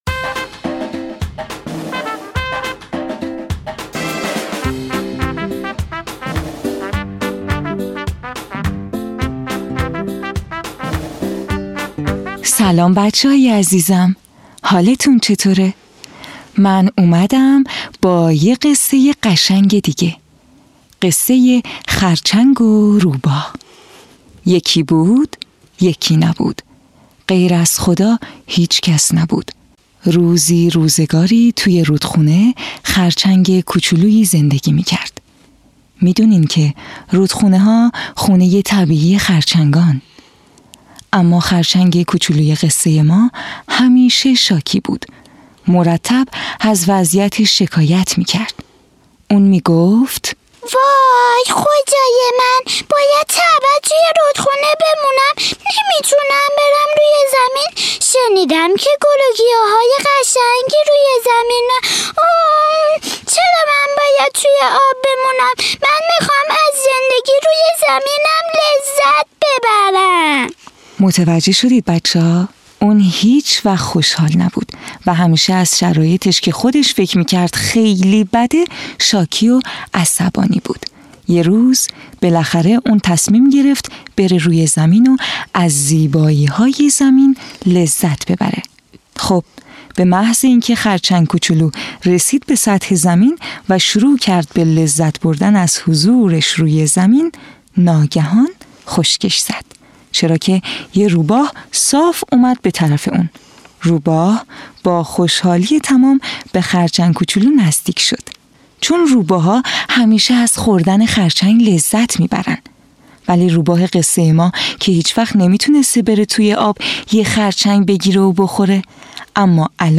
قصه های کودکانه صوتی- این داستان: خرچنگ و روباه
تهیه شده در استودیو نت به نت